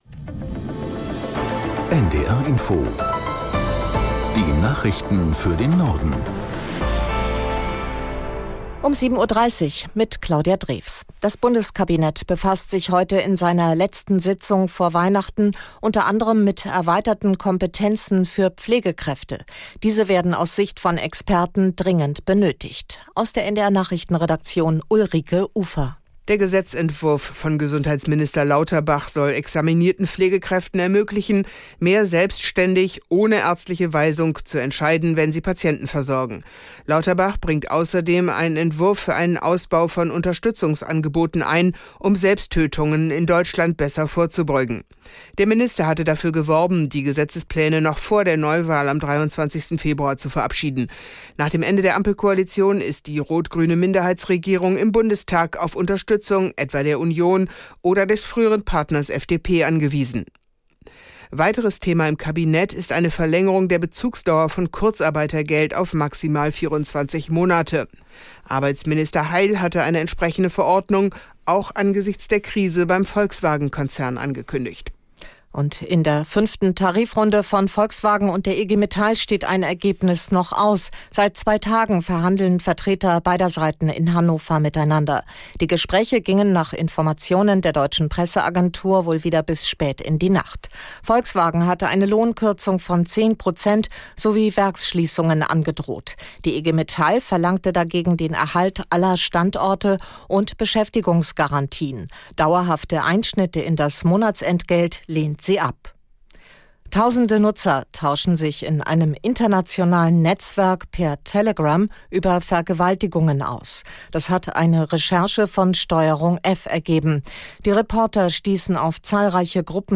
1 Nachrichten 6:23 Play Pause 34m ago 6:23 Play Pause เล่นในภายหลัง เล่นในภายหลัง ลิสต์ ถูกใจ ที่ถูกใจแล้ว 6:23 Die aktuellen Meldungen aus der NDR Info Nachrichtenredaktion.